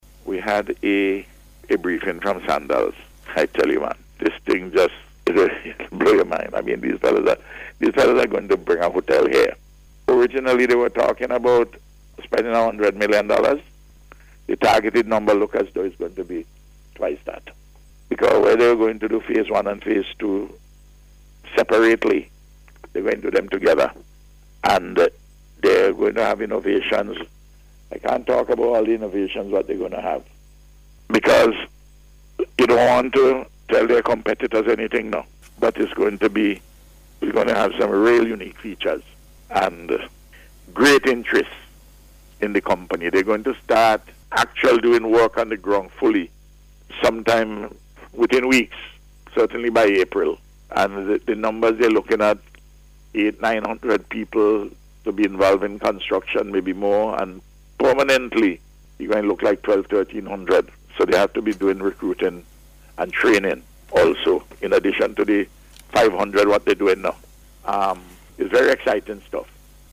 Prime Minister Dr. Ralph Gonsalves said on NBC Radio on Wednesday that Sandals Resorts International will be increasing its investment in the Resort at Buccament Bay.